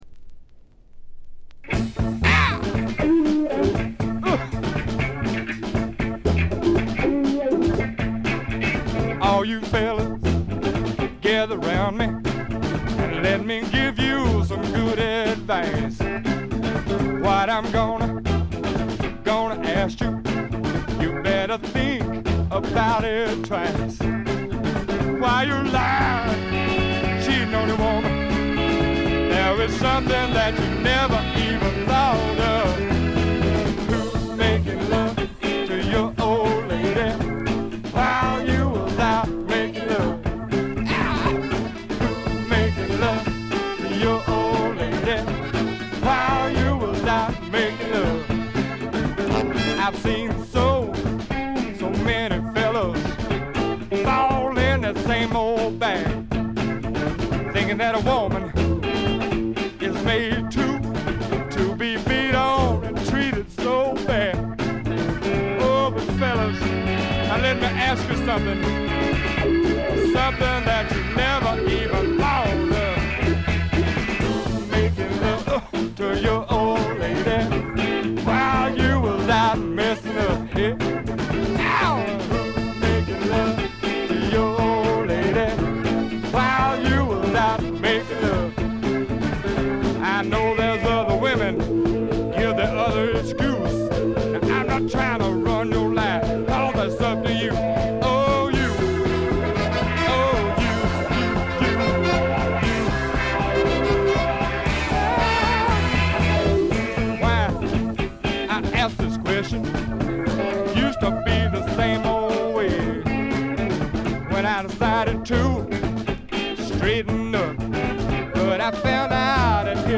チリプチ、プツ音多め大きめ。
スワンプ基本！
試聴曲は現品からの取り込み音源です。
Guitar, Harmonica